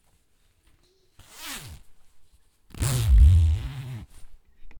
Cremallera
Grabación sonora que capta el sonido de una cremallera cerrándose o abriéndose (fricción entre las cadenas de dientes al cierre/apertura).
fricción